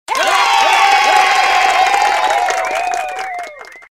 Play, download and share aplauso original sound button!!!!
aplauso-002.mp3